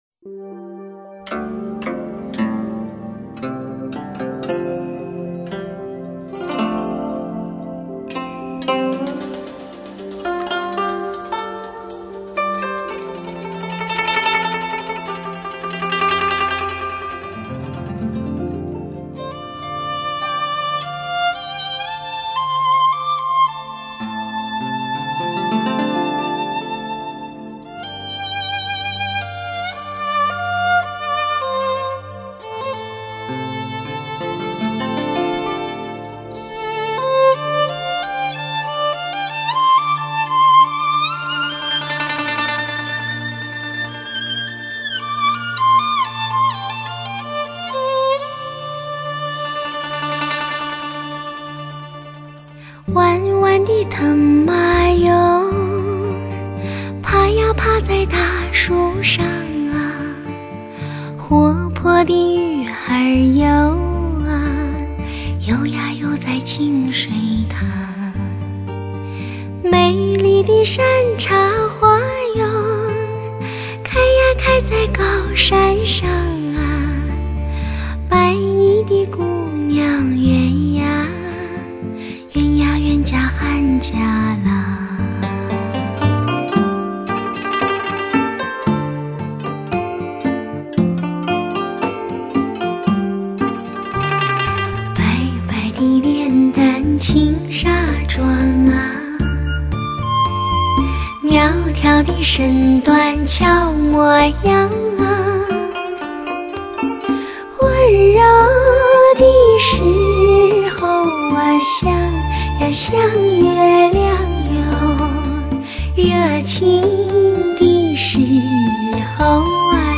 一张音质拨尖的示范级佳作，百听不厌，给你至尊享受。